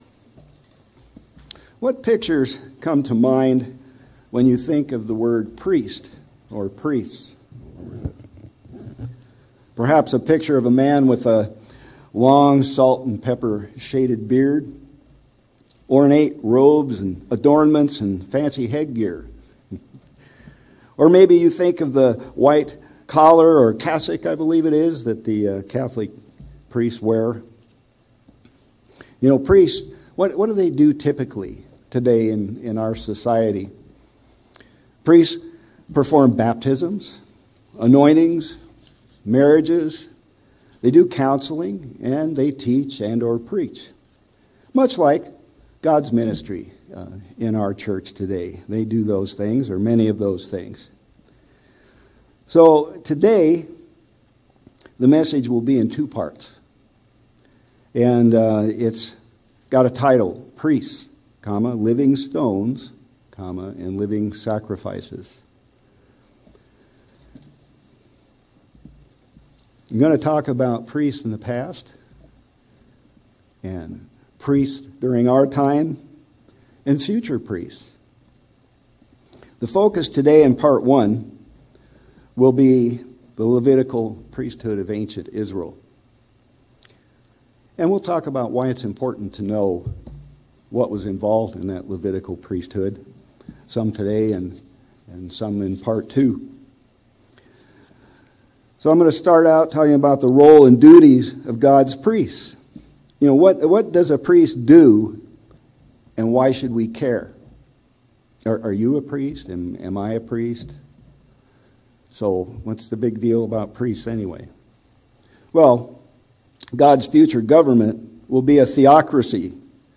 In this first of a three-sermon series, we begin to explore what a priest’s duties were in the Old Testament and why.